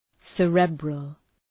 Shkrimi fonetik{‘serəbrəl, sə’ri:brəl}
cerebral.mp3